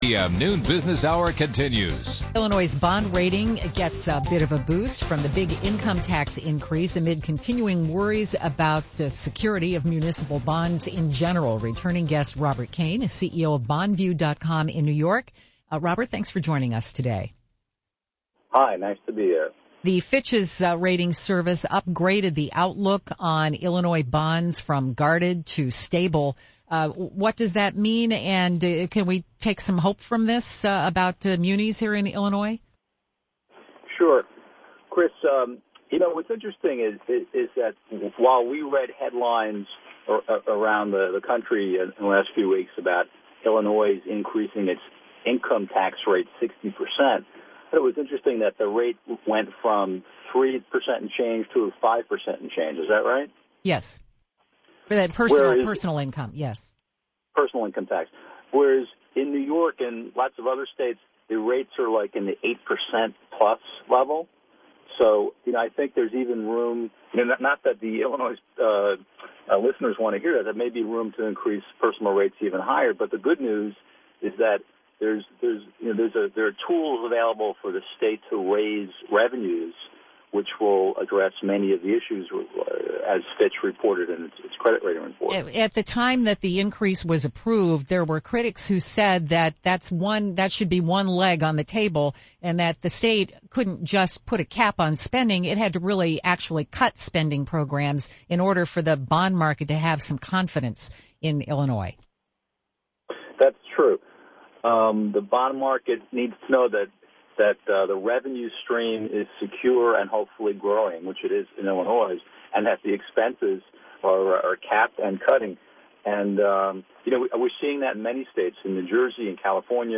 Frank Partnoy Interview on NPR, 8/17/11